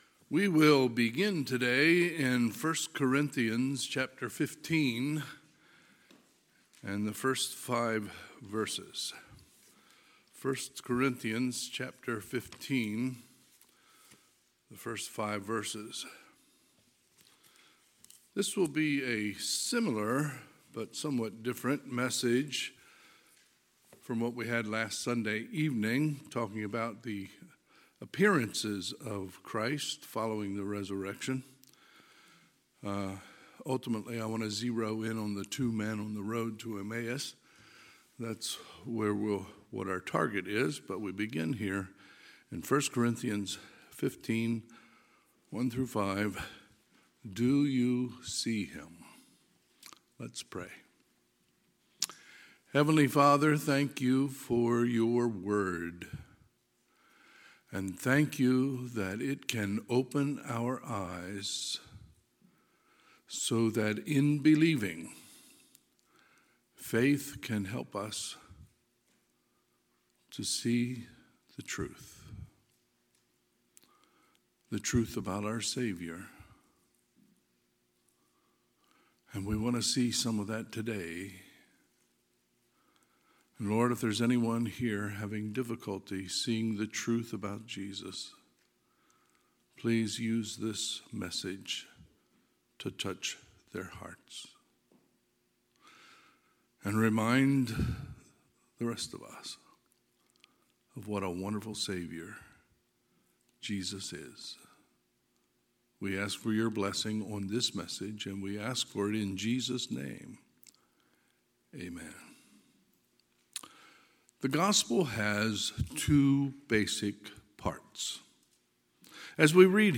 Sunday, April 16, 2023 – Sunday AM
Sermons